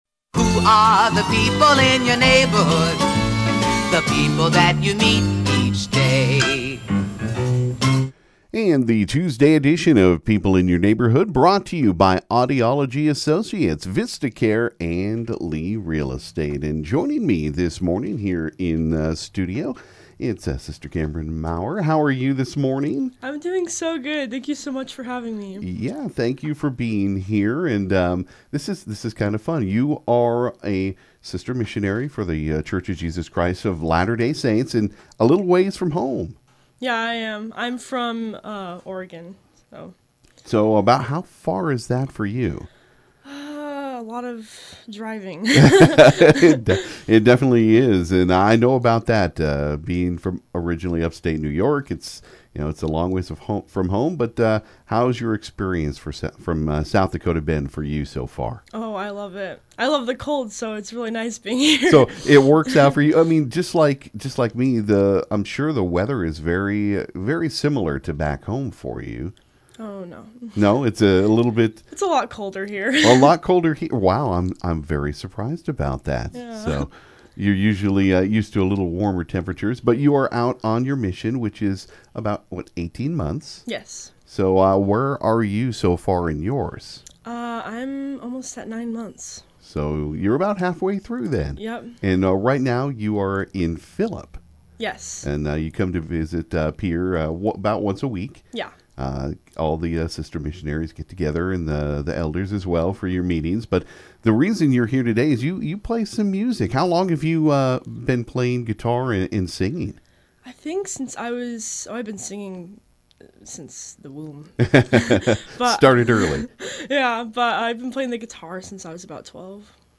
Chats & Sings
on KGFX
where she grew up and shared her music including playing one of her original songs live.